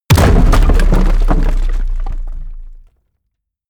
Royalty free sounds: Impacts